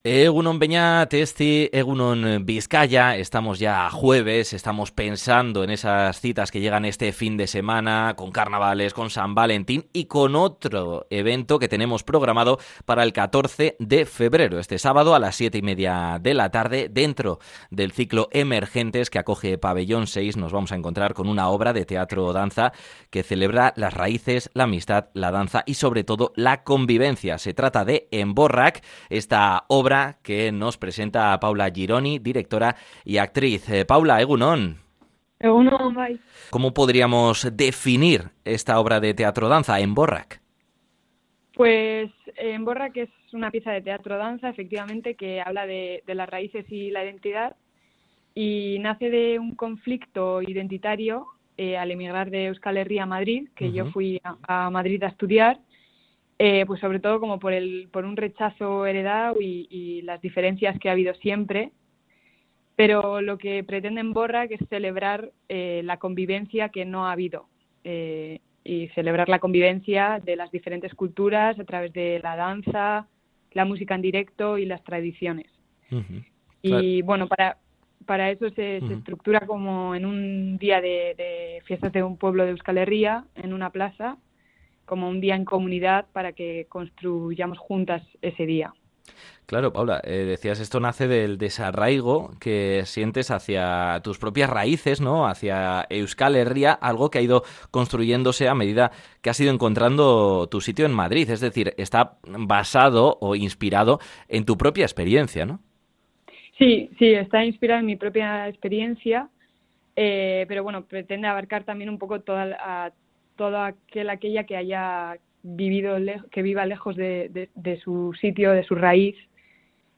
Podcast Cultura